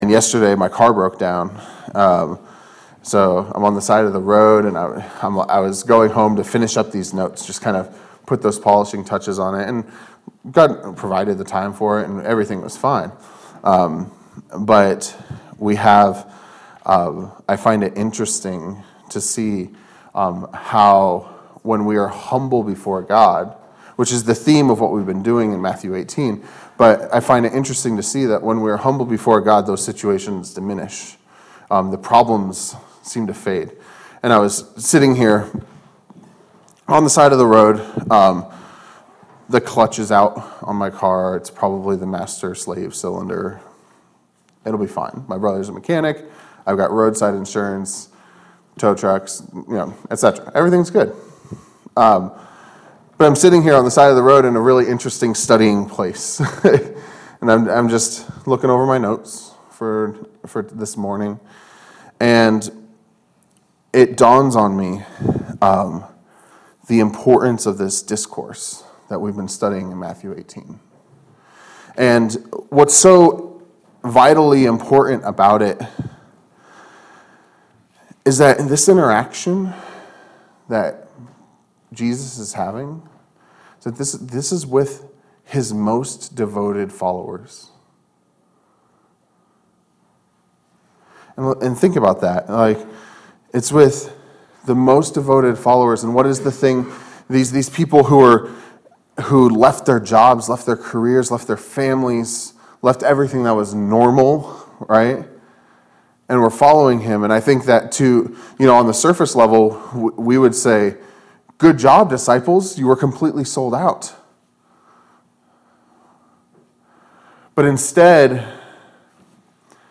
A message from the series "Matthew." Matthew 26:57-68